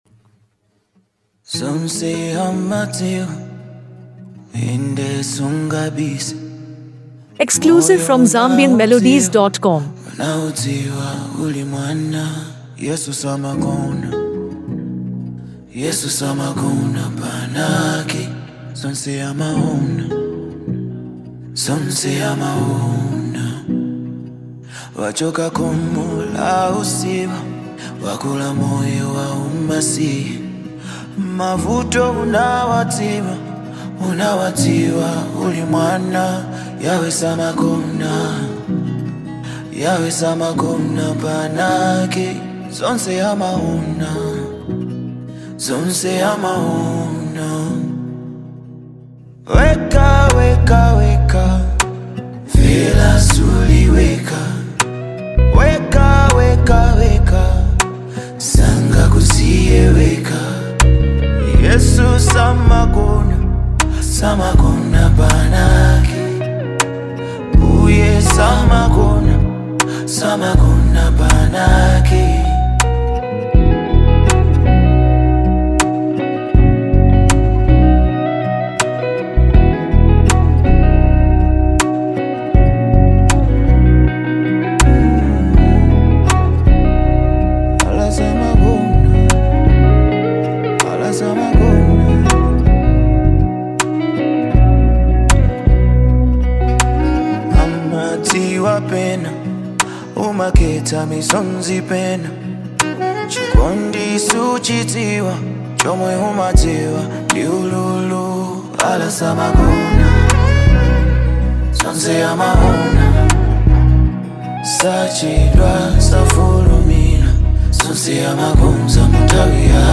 A Soulful Masterpiece
Known for his unique blend of Afro-soul, Afro-pop